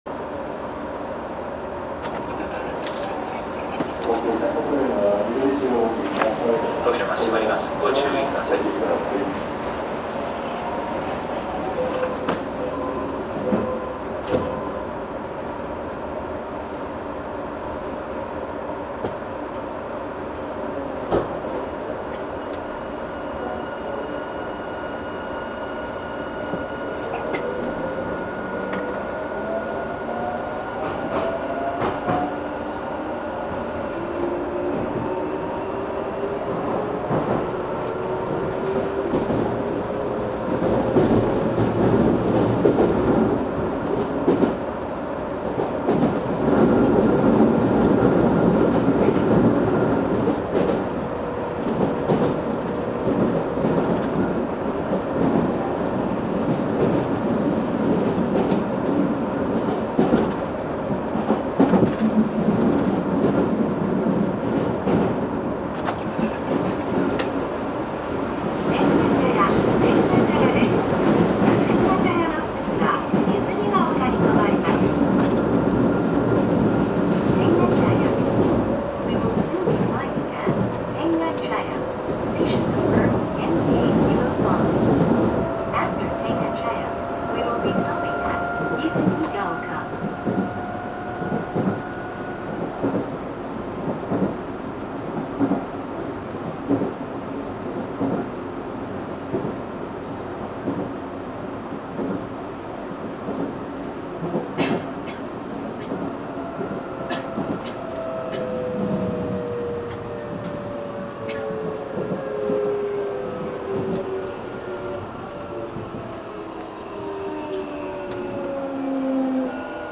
走行機器はIGBT素子によるVVVFインバータ制御で、定格180kWのMB-5091-A2形かご形三相誘導電動機を制御します。
走行音
元泉北高速鉄道所有車
録音区間：新今宮～天下茶屋(泉北ライナー69号)(お持ち帰り)